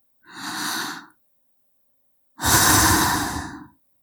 语气词-难过.wav